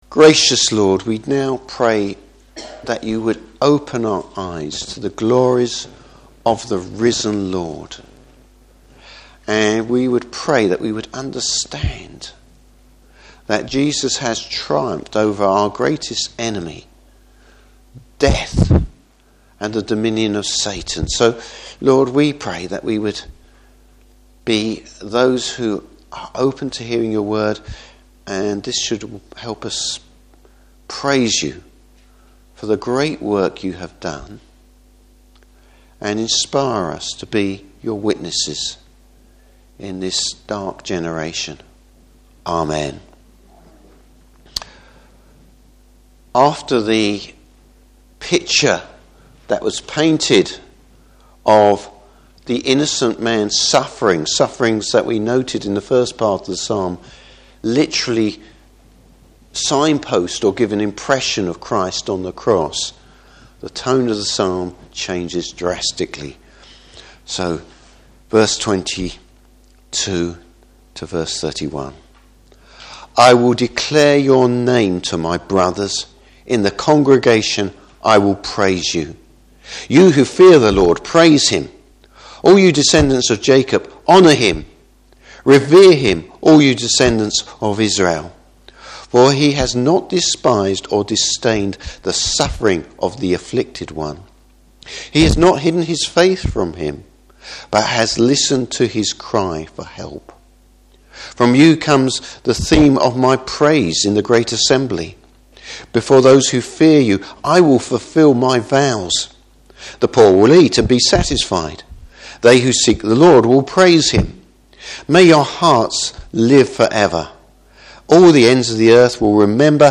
Service Type: Easter Day Morning Service.